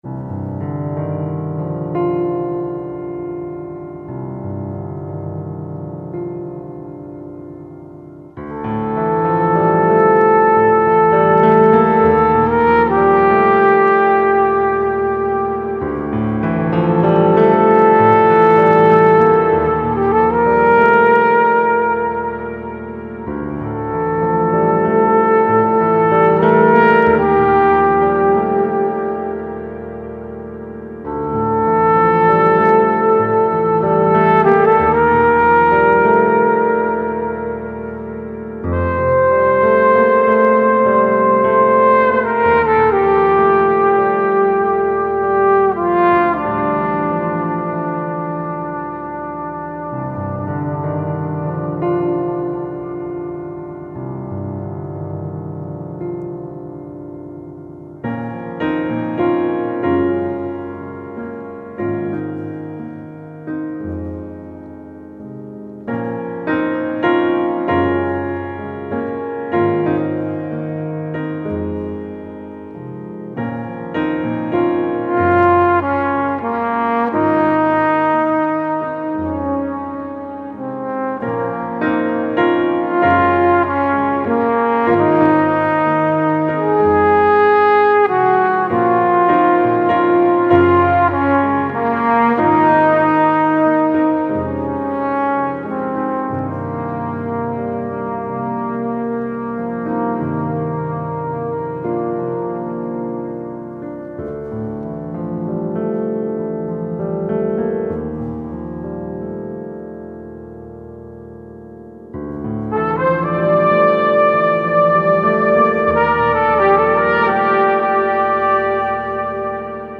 flugelhorn or trumpet